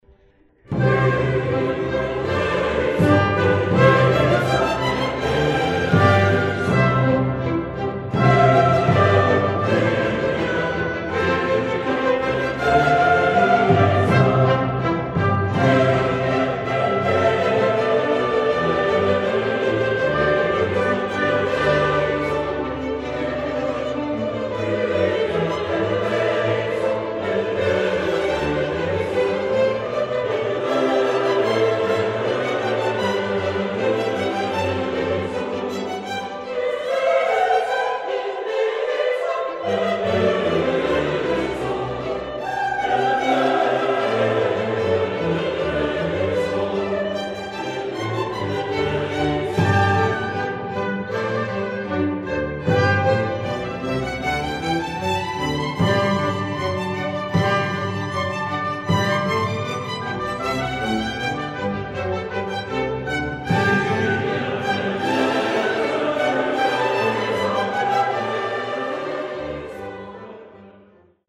Sopran
Alt
Tenor
Bass
Chor und Orchester von St. Peter